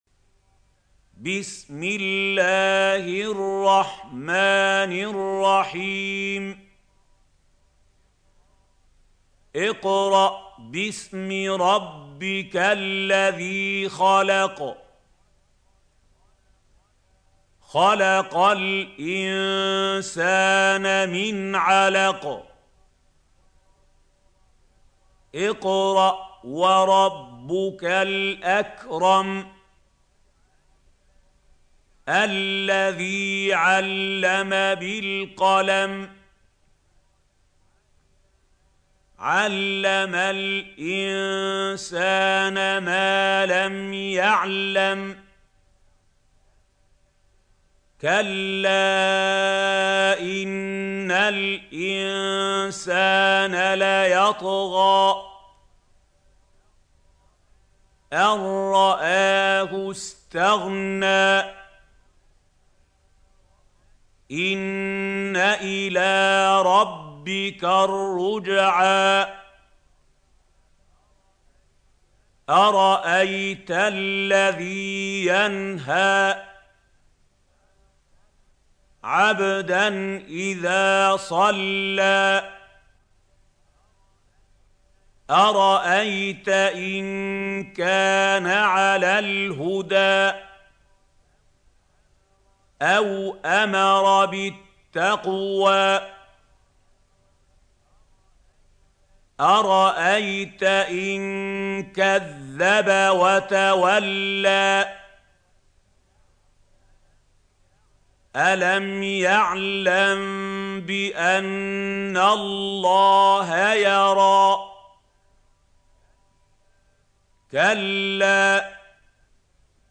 سورة العلق | القارئ محمود خليل الحصري - المصحف المعلم